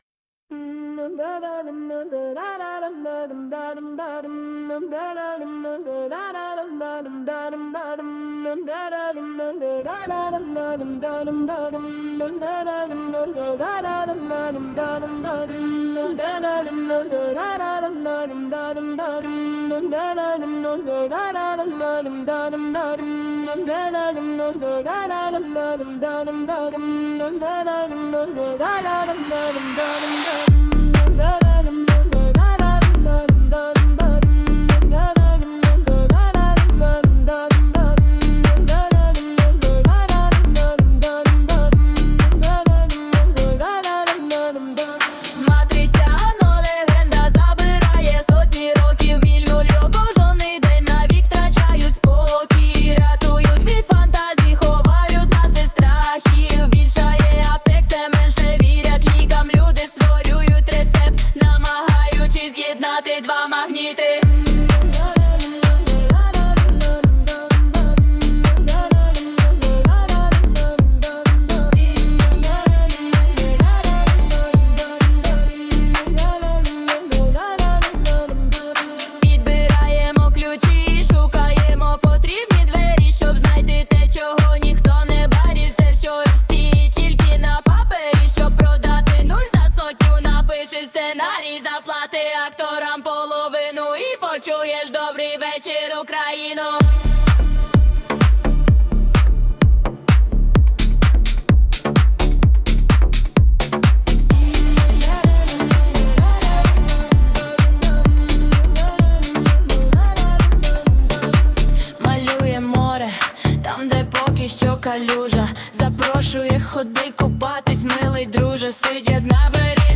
Матриця (+🎧музична версія)
СТИЛЬОВІ ЖАНРИ: Ліричний